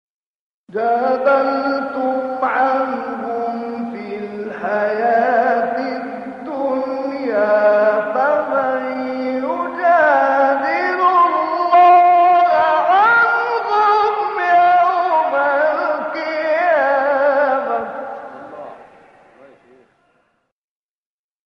استاد «محمد عبدالعزیز حصان» یکی از مشاهیر تلاوت قرآن کریم است و به لحاظ برجستگی در ادای نغمات و دقت در ظرافت‌های وقف و ابتداء او را «ملک الوقف و الإبتدا و التنغیم» یعنی استاد الوقف و الابتداء و تلوین النغمی لقب داده‌اند.
در ادامه ۵ قطعه کوتاه از زیباترین تلاوت‌های استاد محمد عبدالعزیز حصان ارائه می‌شود.